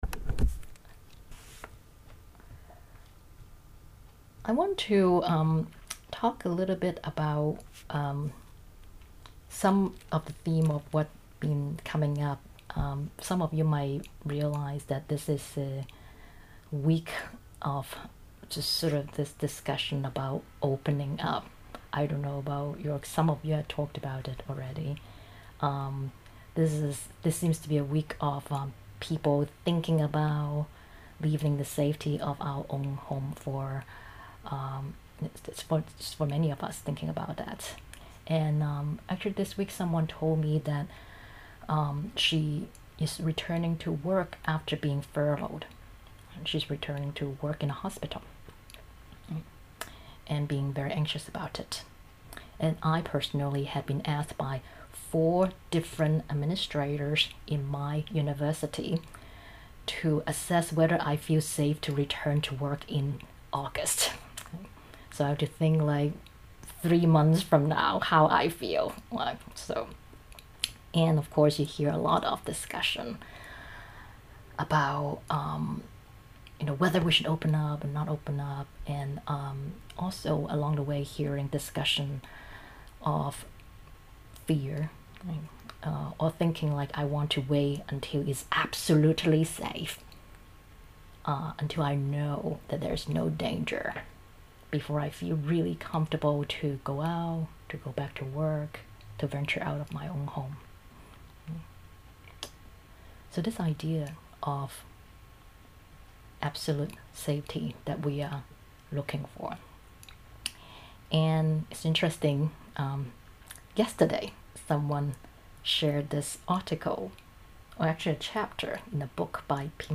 This talk was given in the weekly online Dharma practice gathering on May 15, 2020.